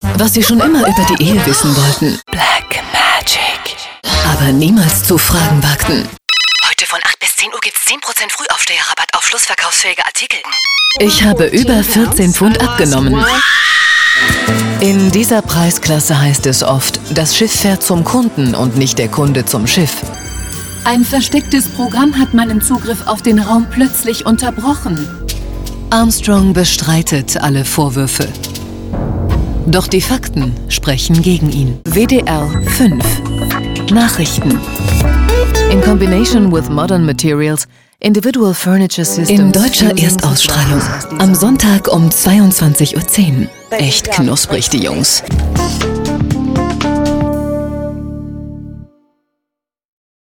She is known for her versatile, warm and sovereign voice.
Sprechprobe: Werbung (Muttersprache):
german female voice over talent. Her extensive media experience includes leading positions in television as well as direction of radio commercials and event presentation